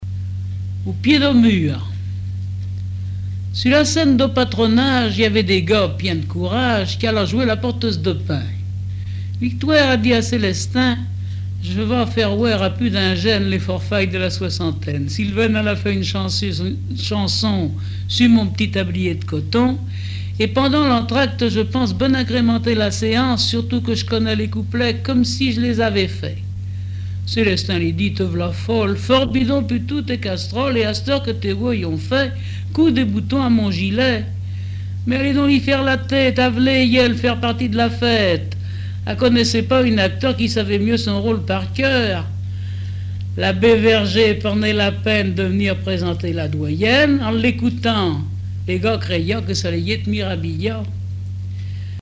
Localisation Chauvé
Langue Patois local
Genre récit